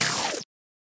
bug_death1.mp3